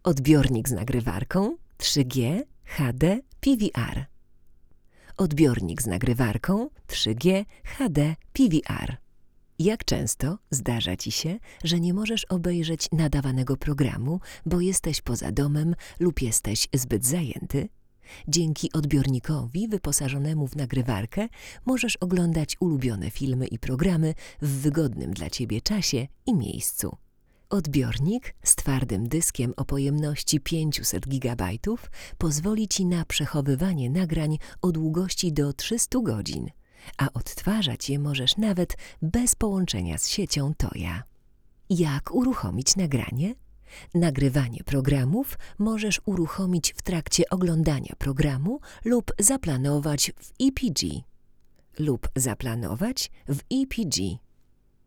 Do testów używamy popularnego Neumanna TLM 103, i dla równowagi U89 tego samego producenta.
Brzmienie tego przedwzmacniacza naprawdę nie pozostawia wiele do życzenia.